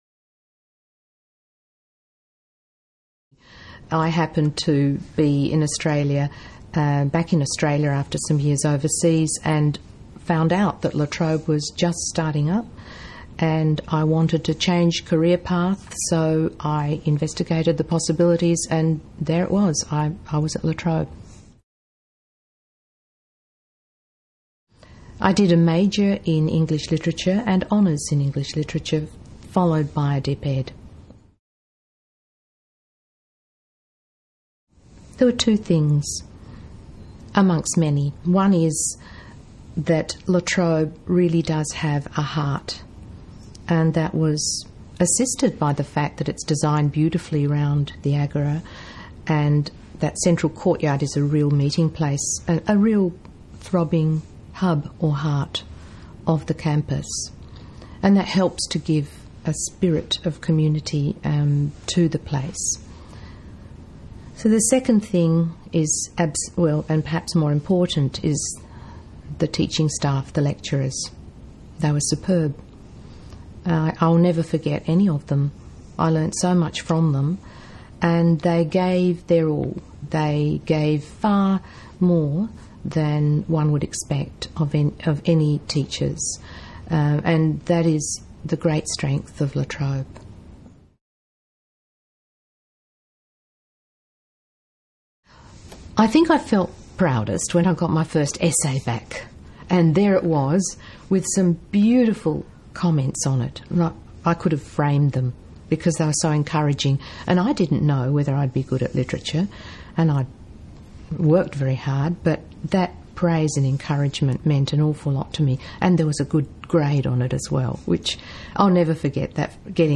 We were fortunate to have the opportunity to interview some of the Distinguished Alumni Awards winners about their time at La Trobe University.